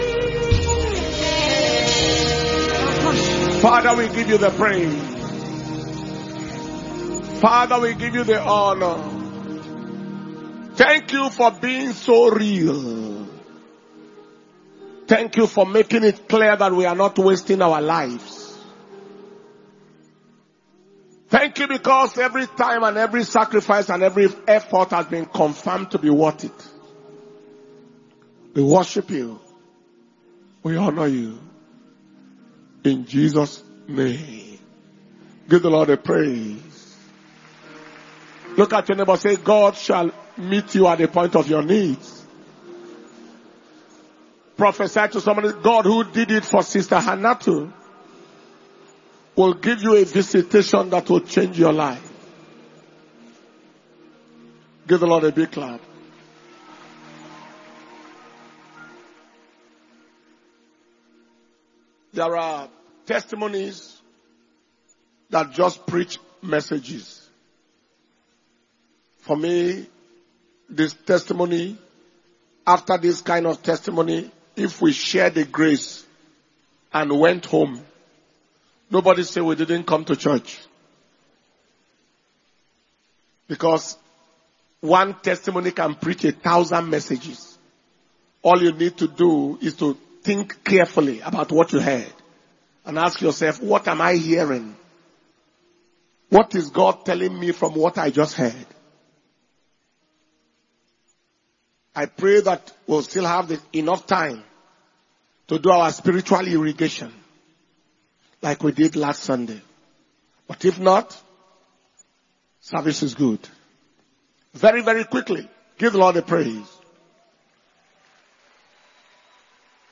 July 2024 Impartation Service